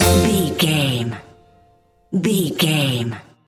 Aeolian/Minor
orchestra
percussion
silly
circus
goofy
comical
cheerful
perky
Light hearted
quirky